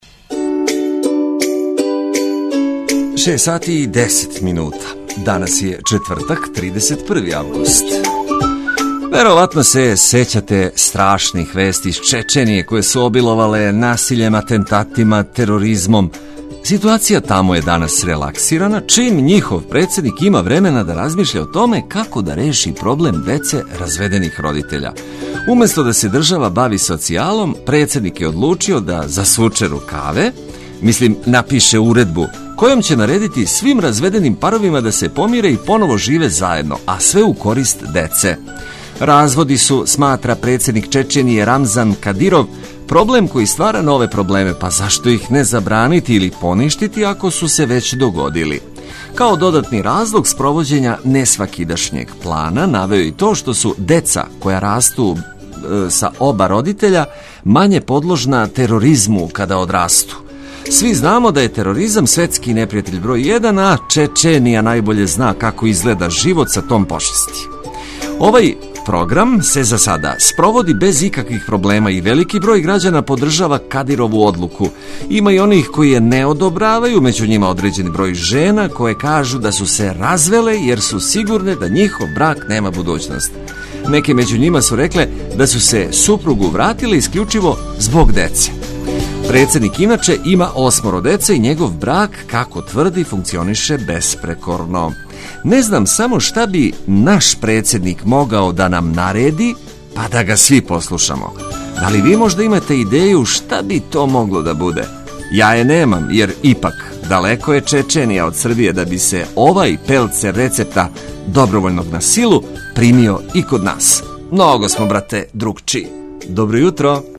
У пријатном друштву сјајно расположене екипе, добре музике и корисних прича дочекујемо изазове новог дана, последњег у августу.